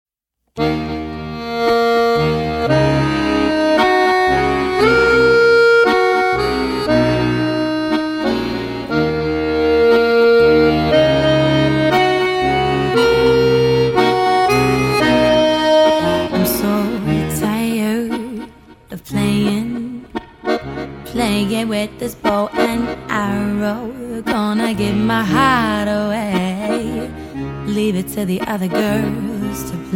accordionist
Although one hears three instruments on most of the tracks